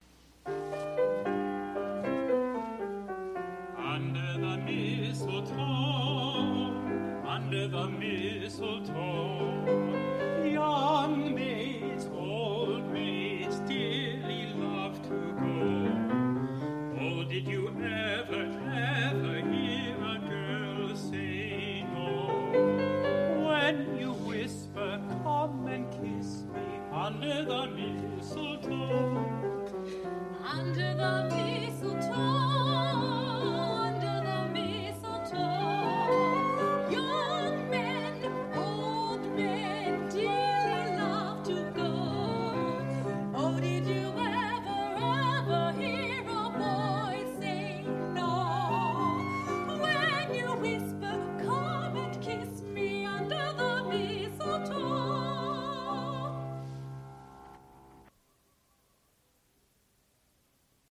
Dear Friends (Musical group)
musical performances, Sound
• sound recording-musical